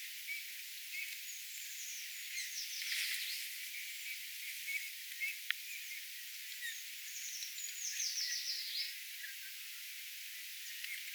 Myös siinä pikkusaaressa kuului kaksi puukiipijän säettä.
puukiipijan_laulua_kaksi_saetta.mp3